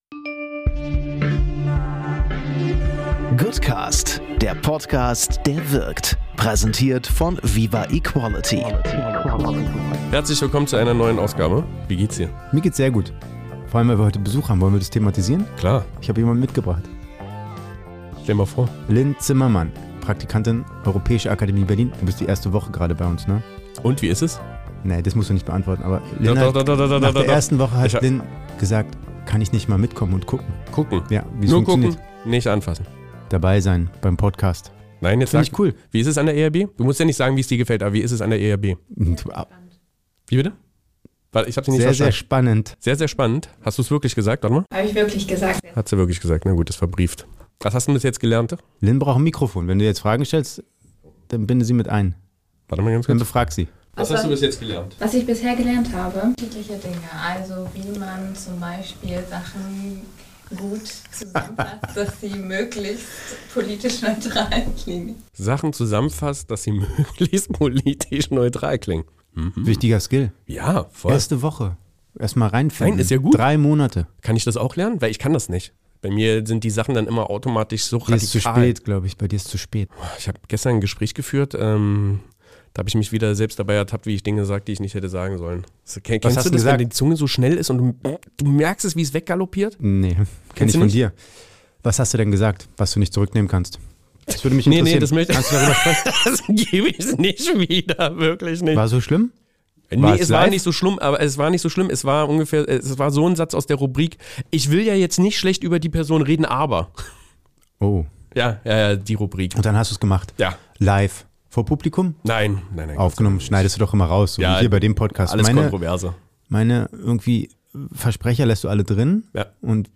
Beschreibung vor 1 Jahr Hibbelig, chaotisch-charmant und voller guter Nachrichten – die Goodnews ballern wieder voll rein!